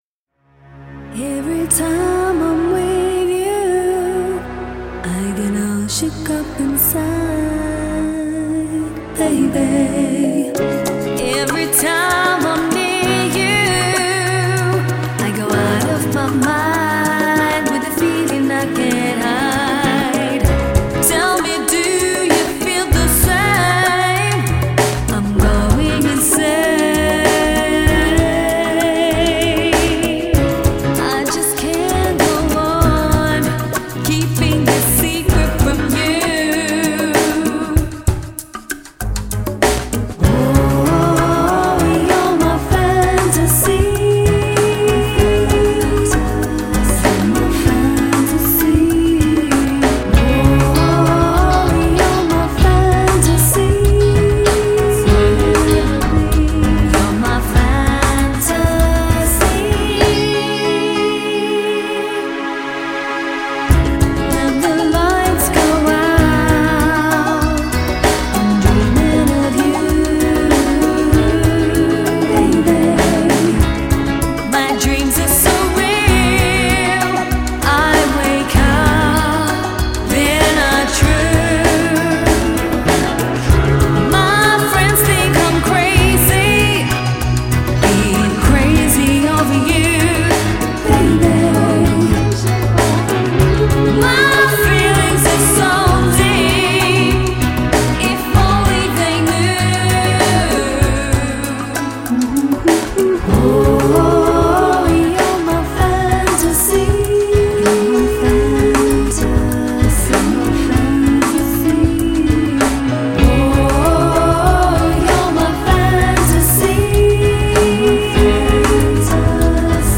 Still a haunting song!